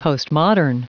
Prononciation du mot postmodern en anglais (fichier audio)
Prononciation du mot : postmodern